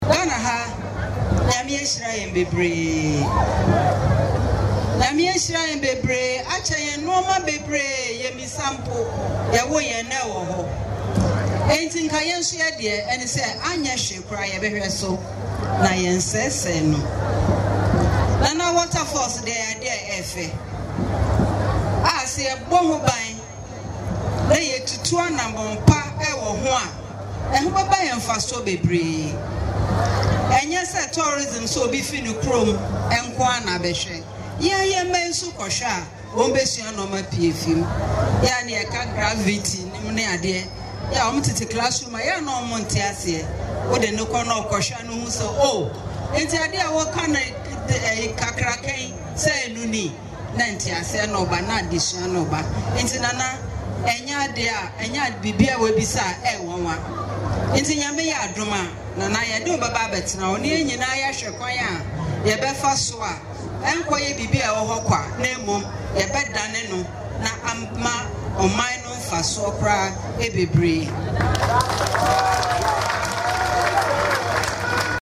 during the climax of the 2024 Elluo Festival.
Prof Jane Naana Opoku Agyeman, NDC Running Mate